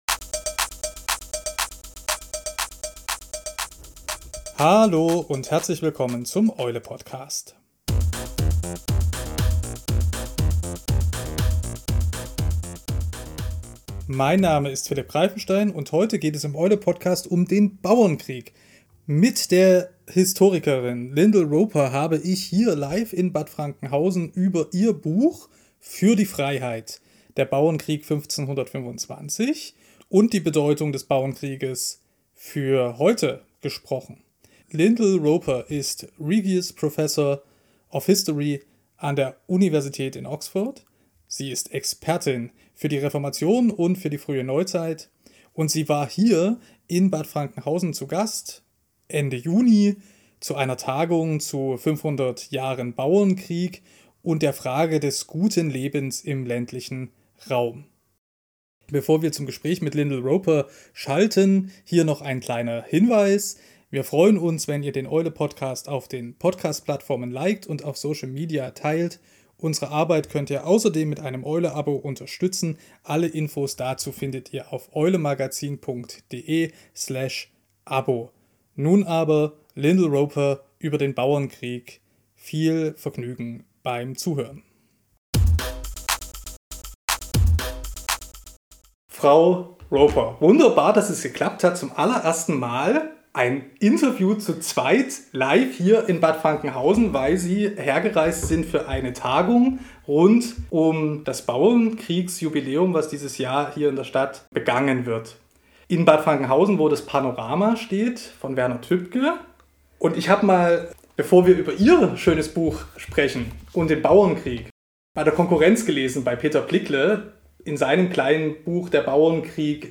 Im „Eule-Podcast“ spricht die Historikerin Lyndal Roper im Anschluss an ihr Buch „Für die Freiheit“ über die historische Bedeutung des Bauernkrieges, Erinnerungskultur(en) und Revolutionen damals und heute.
Aufgenommen wurde diese Episode in Bad Frankenhausen, wo bei einer der größten Schlachten des Bauernkrieges 1525 tausende Aufständige ihr Leben verloren (und heute Die Eule zu Hause ist).